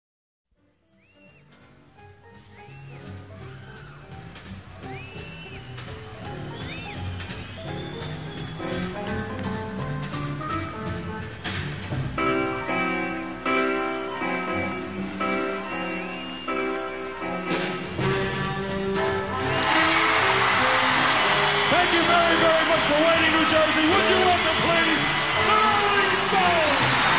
• rock
• registrazione sonora di musica